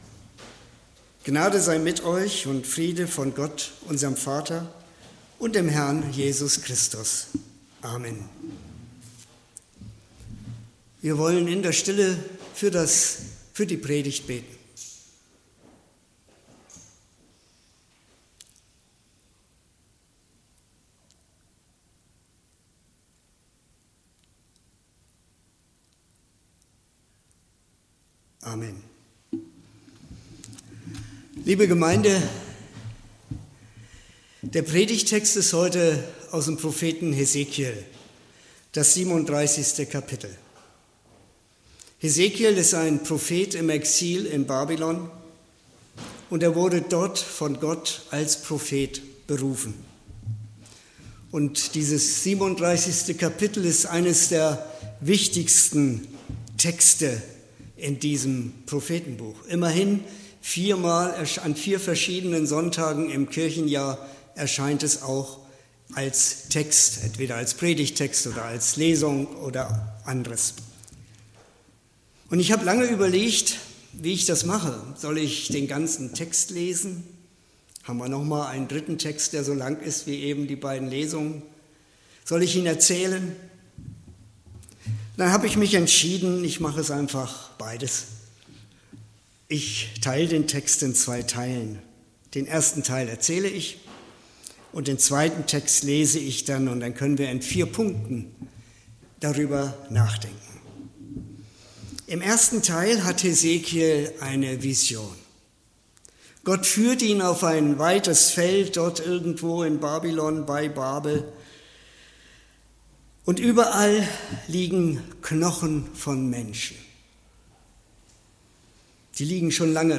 Predigt (Audio): 2024-05-19_Kein_Ende_in_Sicht__Gott_schenkt_Leben_-_neu.mp3 (15,8 MB)